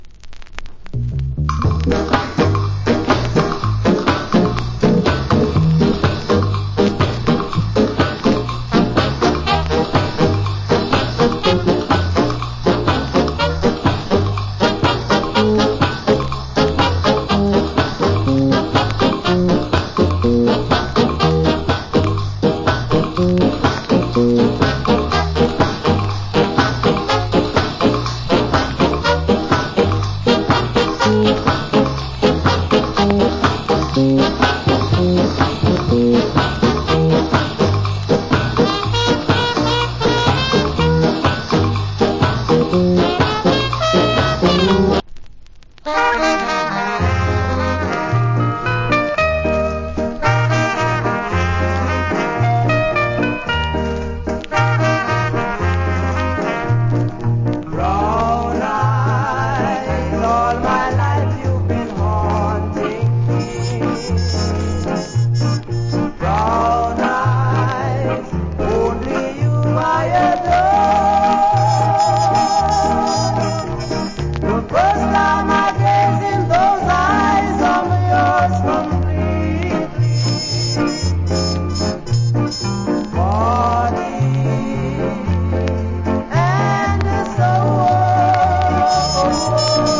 Nice Ska Inst.